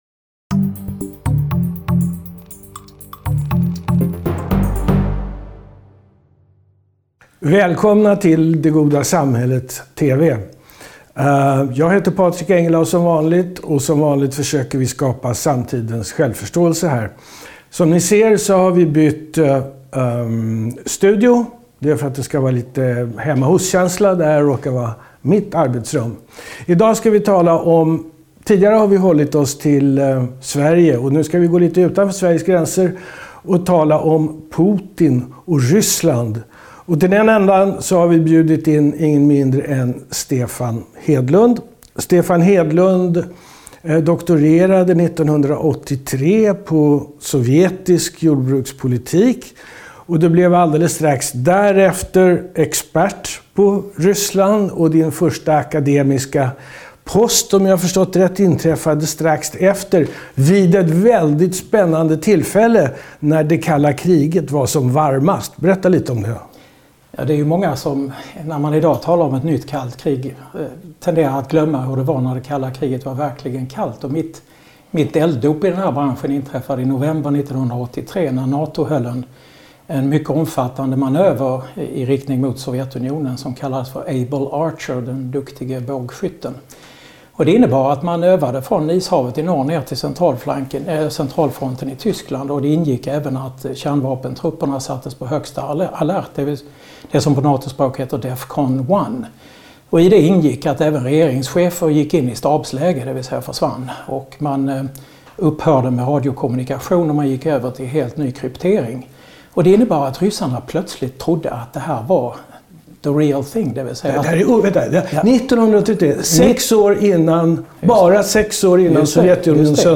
De samtalar om Ryssland, kalla kriget och Vladimir Putin.